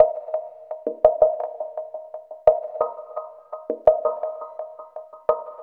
11 Pochahonchos 165 Eb.wav